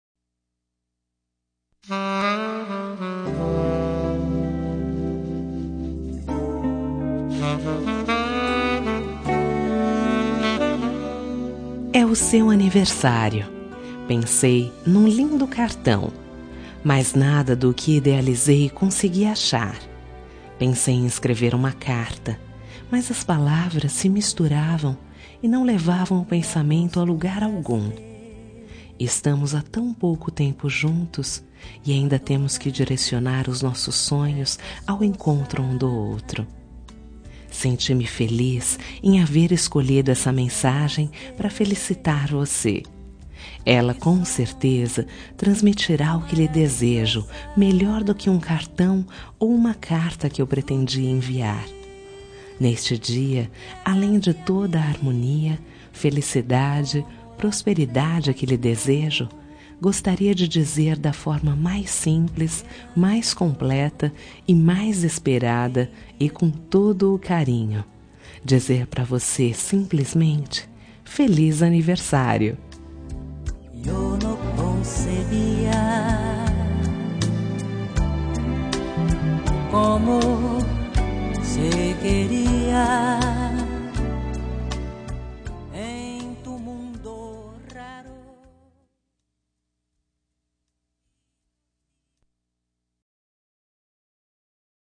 Telemensagem Aniversário de Paquera -Voz Feminina – Cód: 1243
63N 15 Romântica suave.mp3